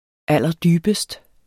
Udtale [ ˈalˀʌˈdyːbəsd ]